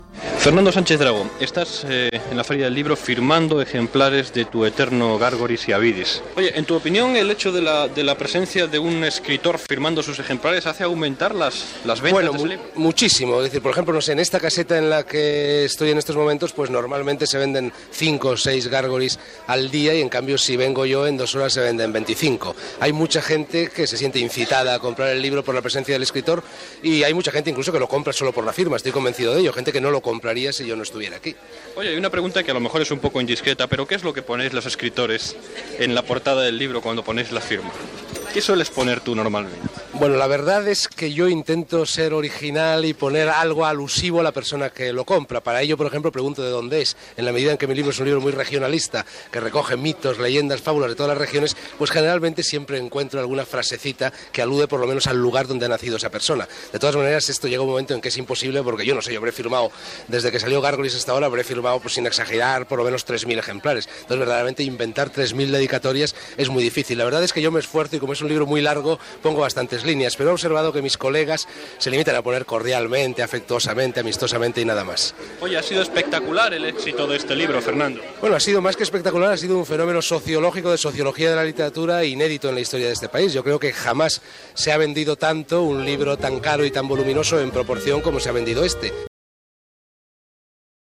Entrevista a l'escriptor Fernando Sánchez Dragó quan estava signant llibres a la Feria del Libro de Madrid